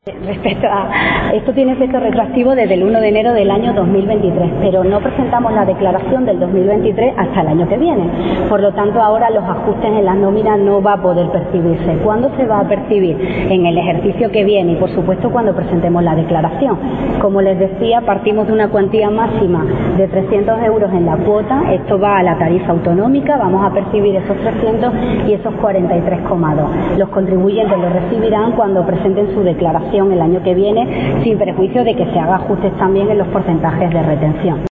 Ello, además, "sin perjuicio de que se hagan ajustes también en los porcentajes de retención", según ha aclarado este viernes en declaraciones a los medios la consejera de Hacienda y Administración Pública de la Junta, Elena Manzano, antes de comparecer en la Asamblea para informar sobre las líneas generales de su departamento.